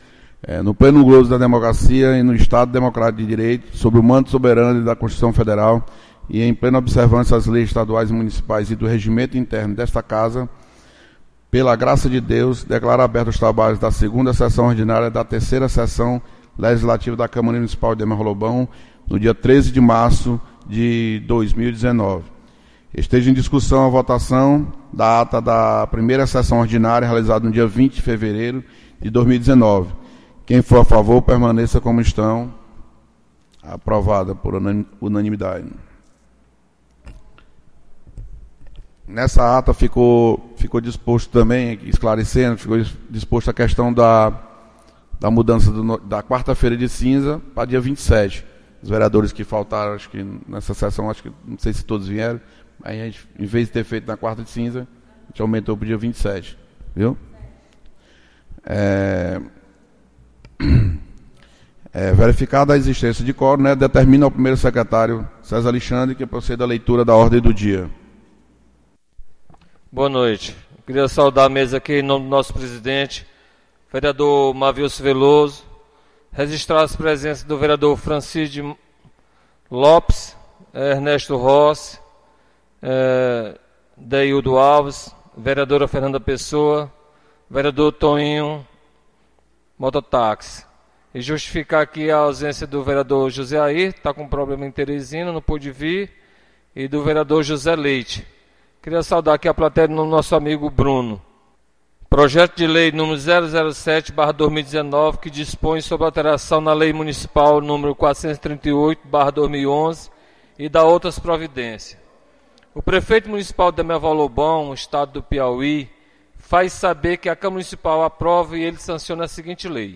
2ª Sessão Ordinária 13 de Março